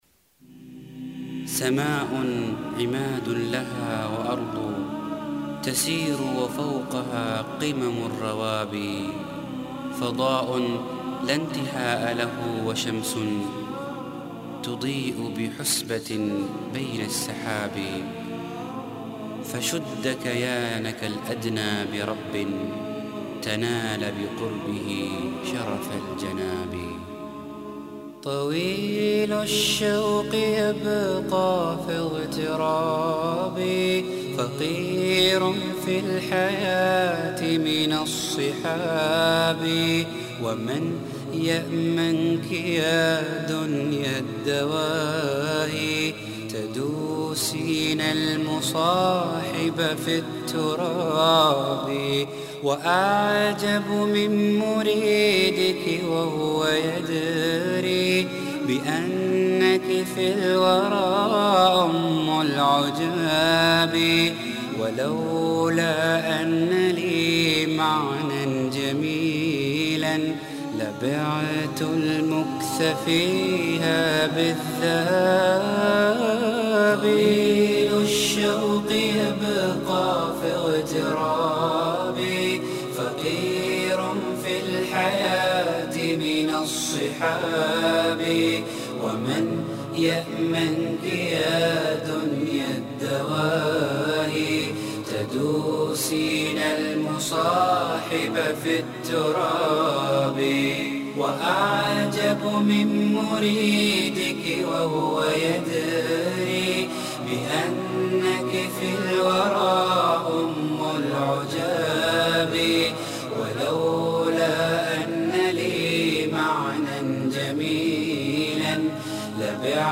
Arabic Islamic Songs